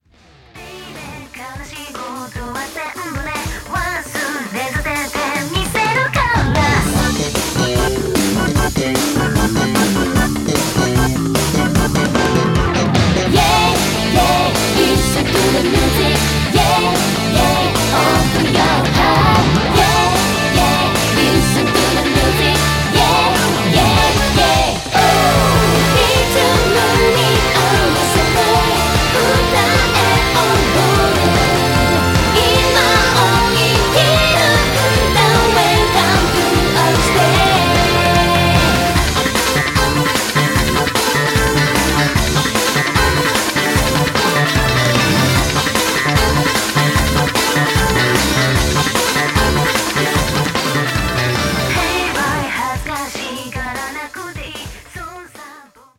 歌詞に込められたメッセージと飾らない真っ直ぐで優しくも力強い歌声。
そして、フルートの音色が心地よく心に響き、極彩色の音と言葉が、夢と勇気と希望を与えてくれる一枚！